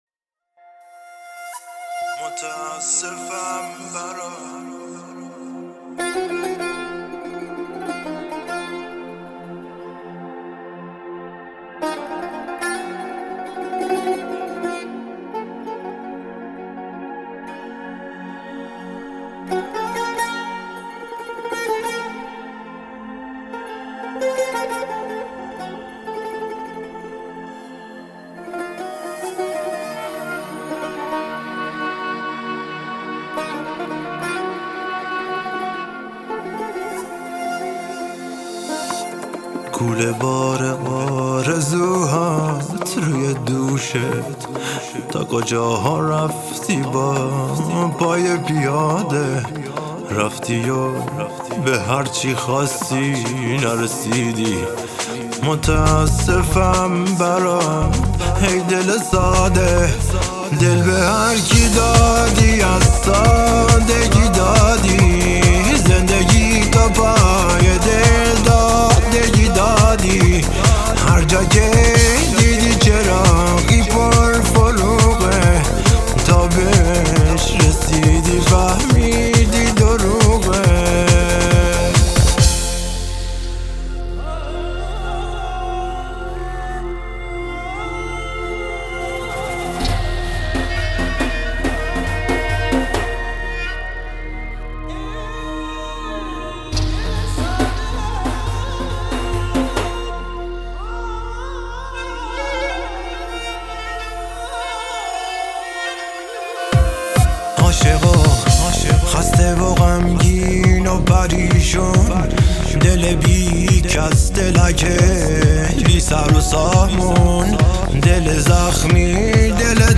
آهنگ شنیدنی و پر احساس